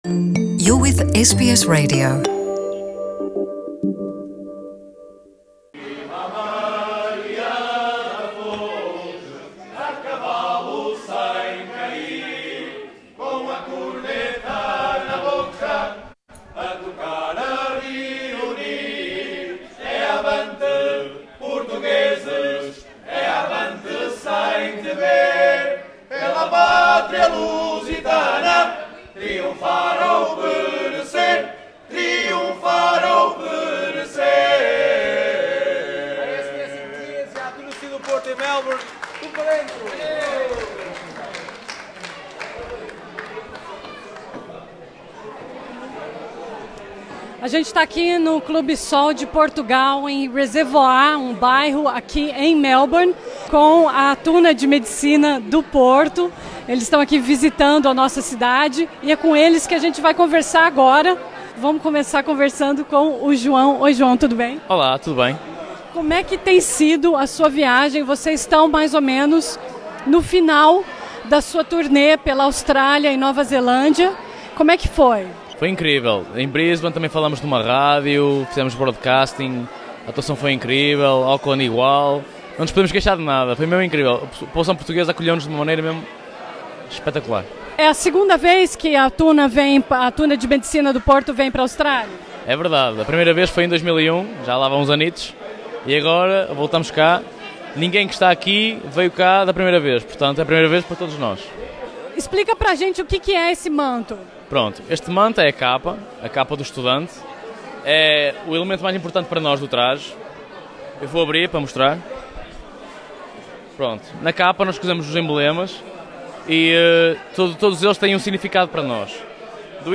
Pela segunda vez em dez anos, a Tuna de Medicina do Porto fez uma turnê pela Austrália e Nova Zelândia. A SBS em Português conversou com os tunos pouco antes da sua penúltima apresentação australiana.